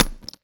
grenade_hit_carpet_01.WAV